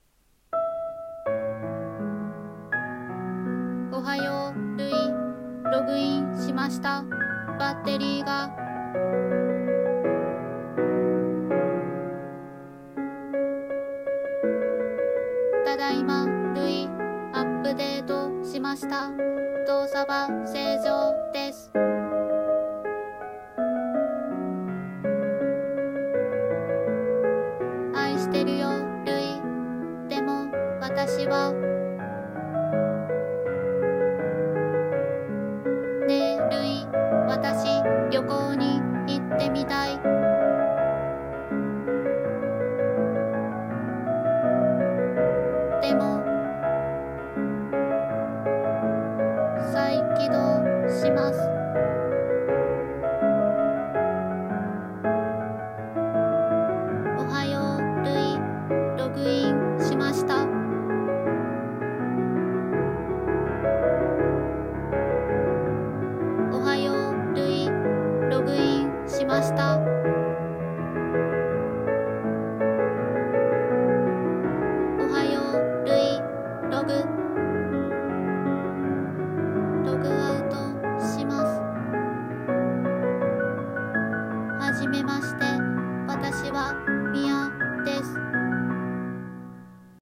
声劇【アンドロイド彼女】